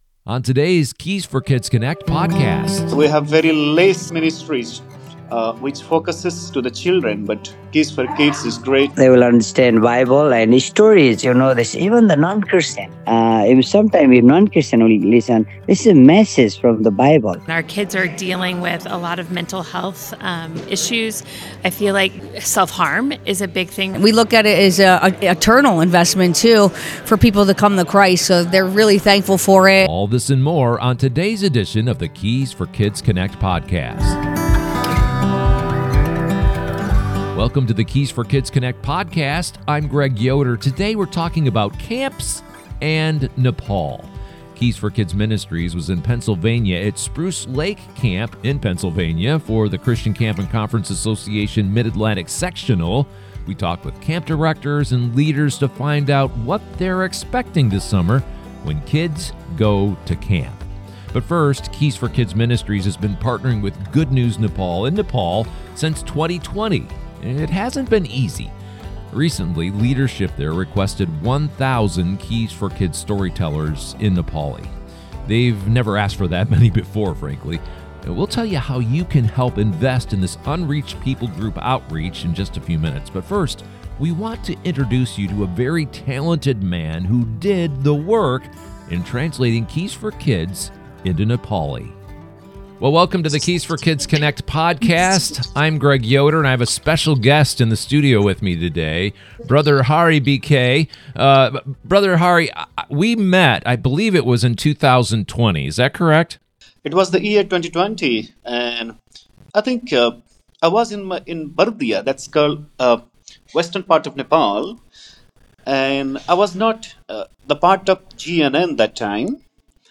four powerful conversations
You’ll hear from ministry leaders who translated Keys for Kids into Nepali